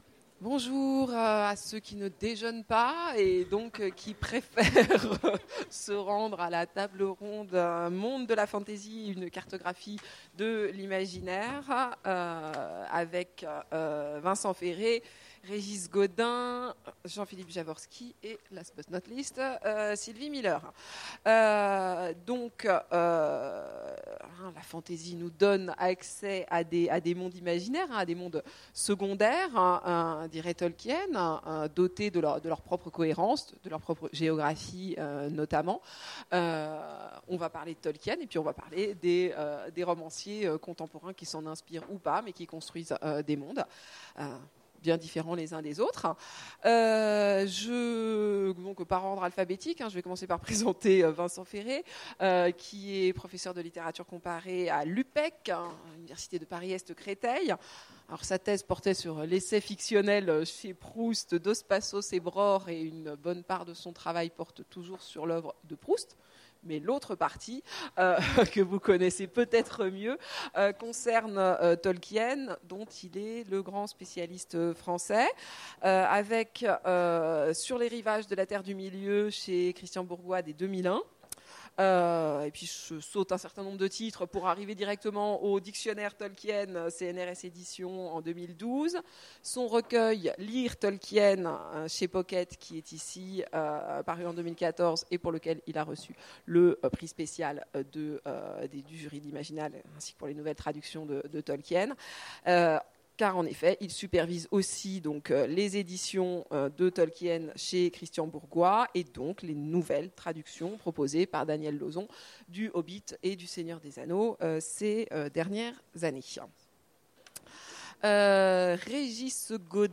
Imaginales 2016 : Conférence Mondes de la fantasy…